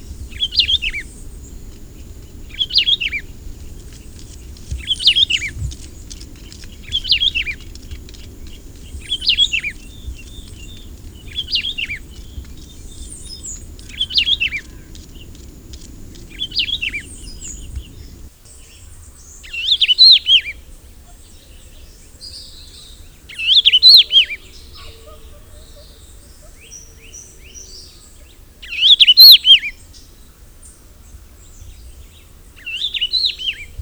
"Puerto Rican Vireo"
Vireo latimeri
El sonoro canto se ve reflejado en el nombre popular para la especie: "bien-te-veo". Cada individuo canta varias versiones de este canto básico a lo largo del día, pero más frecuentemente temprano en la mañana.
bien-te-veo.wav